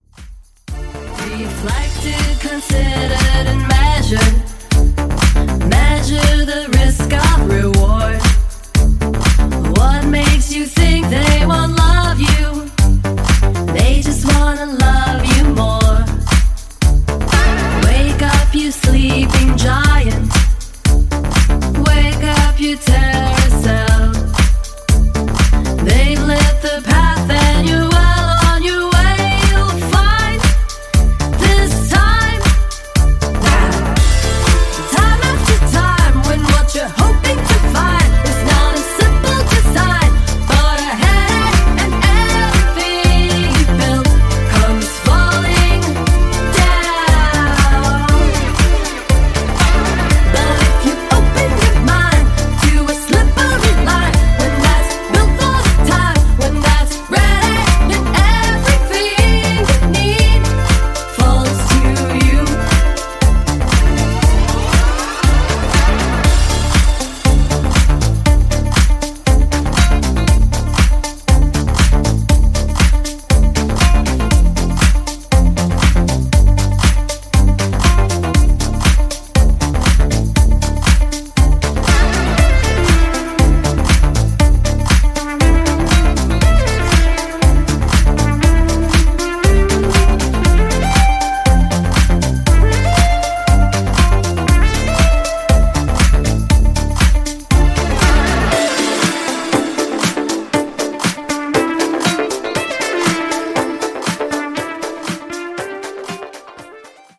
> HOUSE・TECHNO